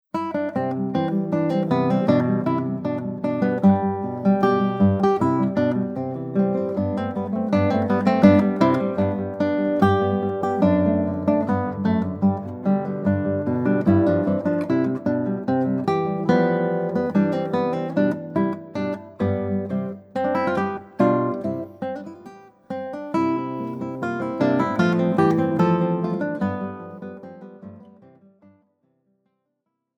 Sechs Jahrhunderte Gitarrenmusik für Gitarrenduo
Besetzung: 2 Gitarren
KLASSIK